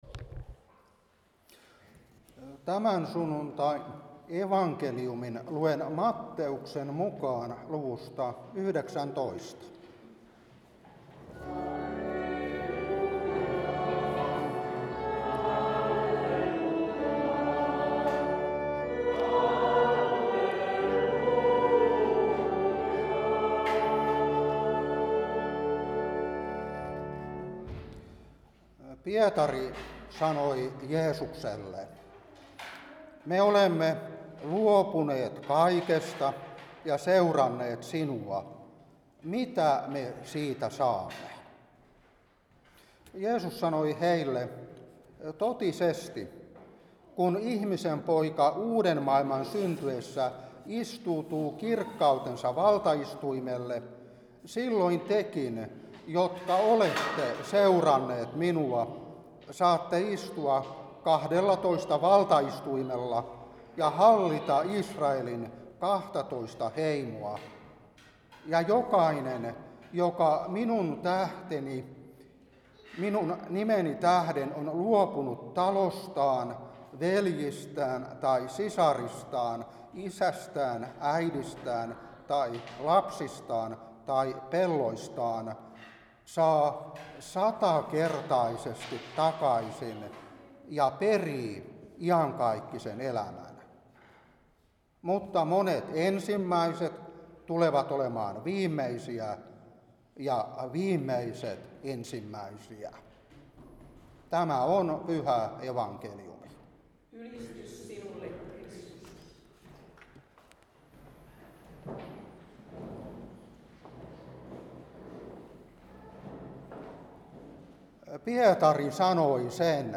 Saarna 2026-1.